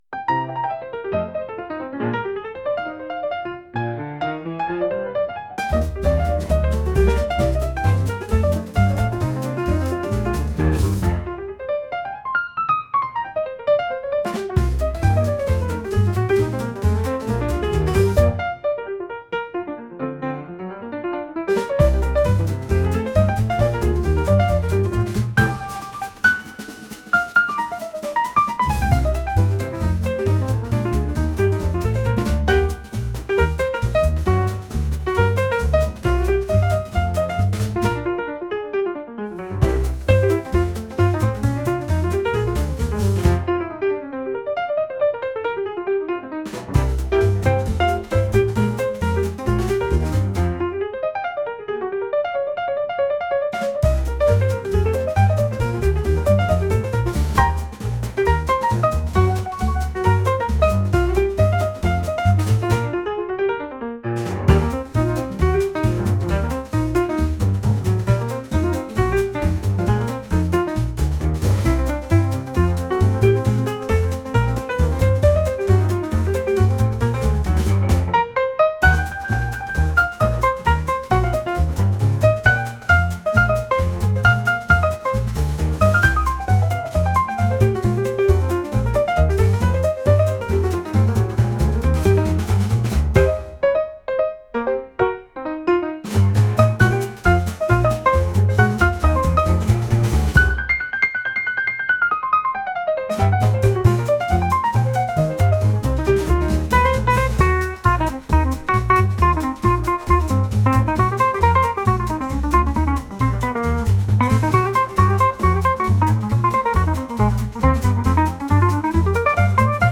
テンポの速いピアノジャズの曲です。